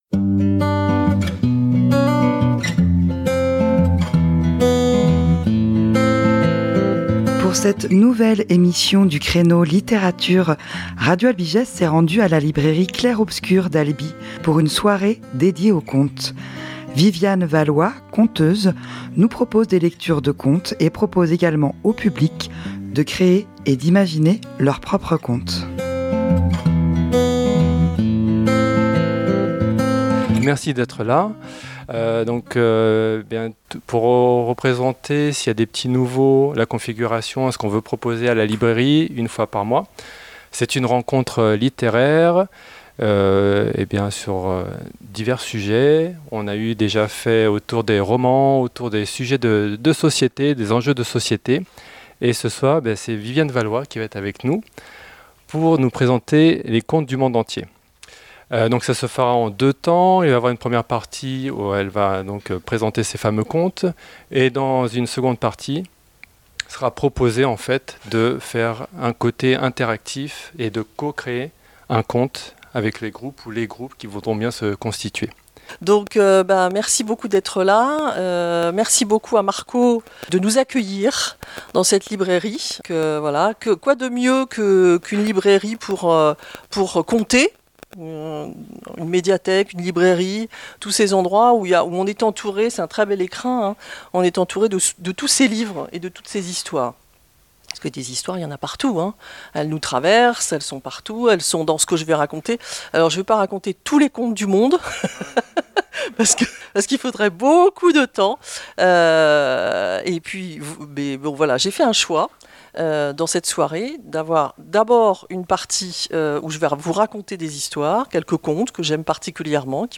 Soirée contes à la Librairie Clair Obscur : découvrez le podcast !
Très beau voyage imaginaire et créatif à la Librairie Clair Obscur à Albi ce vendredi 29 septembre dernier. Grace à Radio Albigès vous pouvez suivre la soirée enregistrée et son podcast !